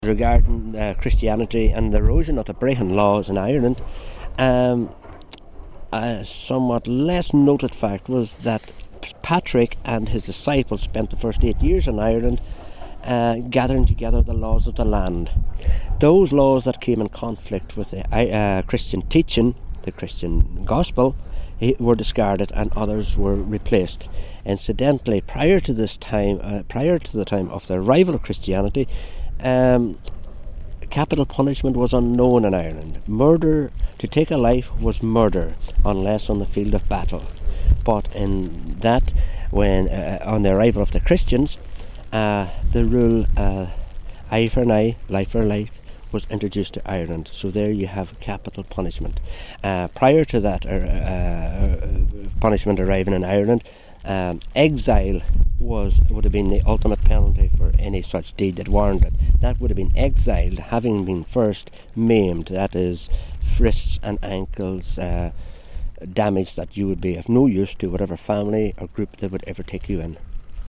out and about in Inishowen